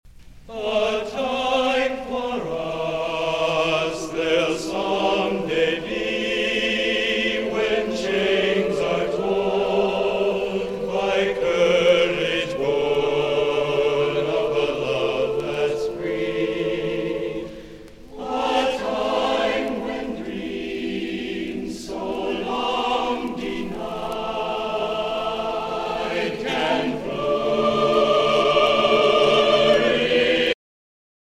January 9-10, 1970 marked its 20th concert.
The Pops Singers performed this song a cappella.
Jazz vocals